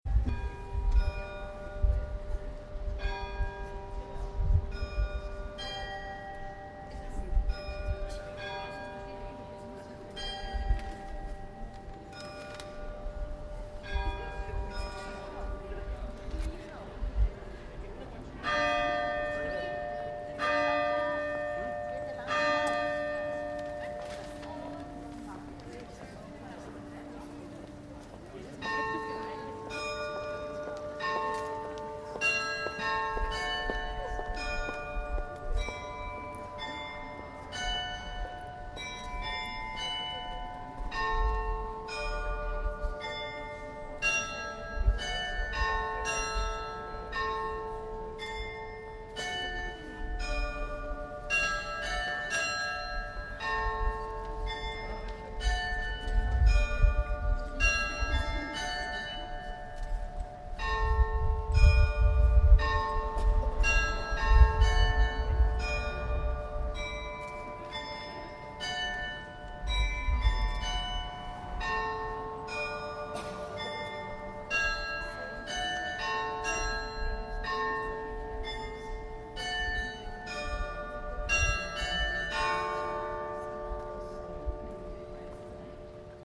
Das städtische Umweltbildungszentrum und das Umweltamt haben an ein paar Orten rund um die Grazer Alt- und Innenstadt, den Klang der Stadt aufgenommen.
Glockenspielplatz erklingt im helleren Klang.
glockenspielplatz.mp3